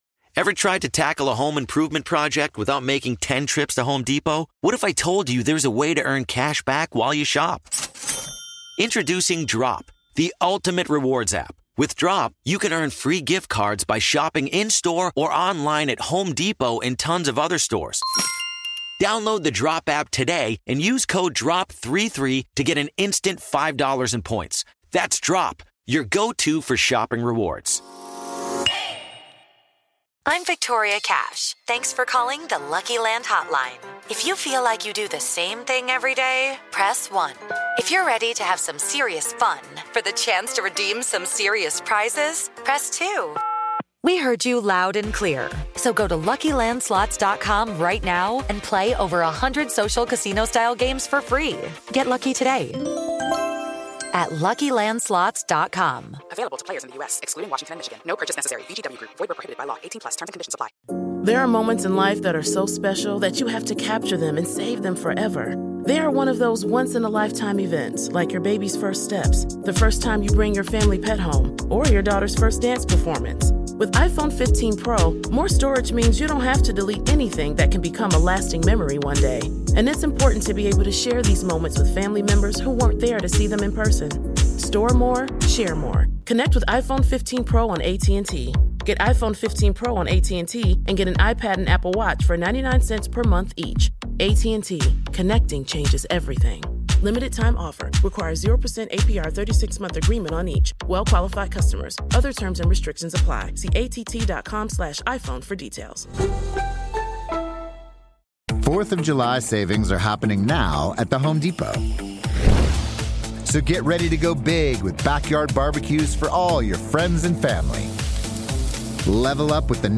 On this episode of Our American Stories, just moments before the biggest fireworks display in American history, President Ronald Reagan spoke in front of the Statue of Liberty aboard the USS John F. Kennedy.
We take you back to New York Harbor in 1986.